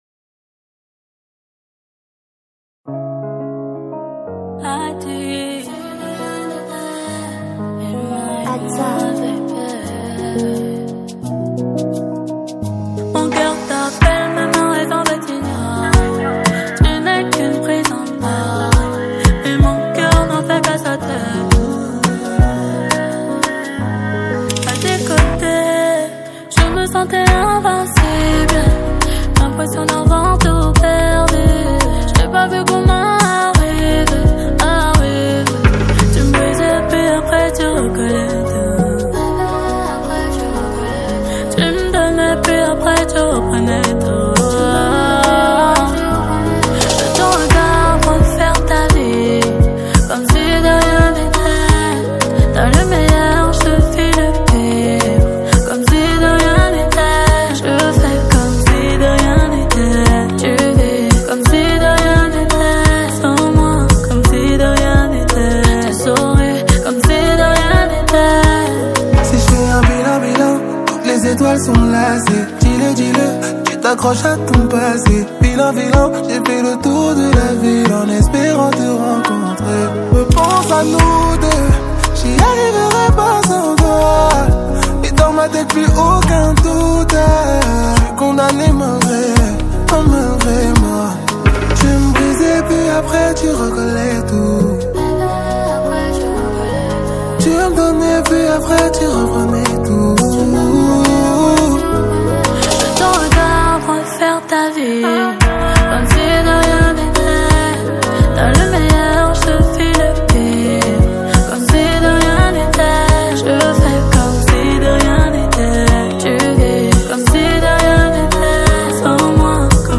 | Afro zouk